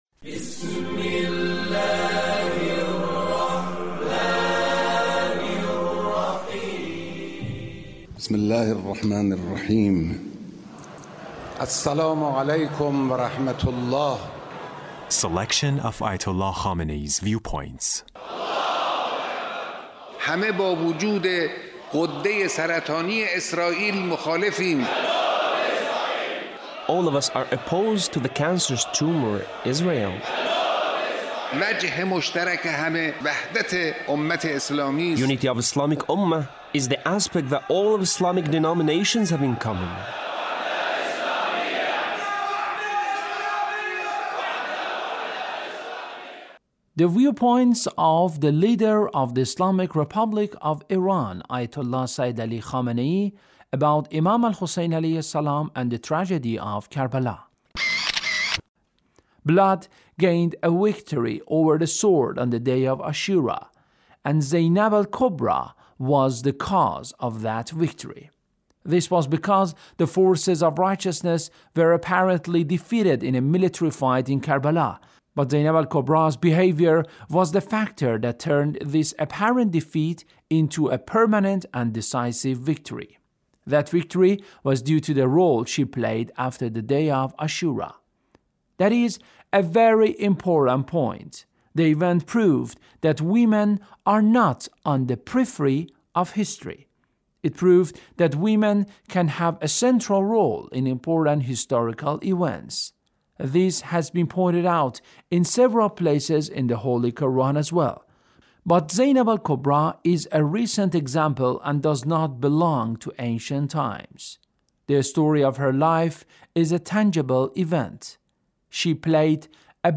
Leader's Speech (1777)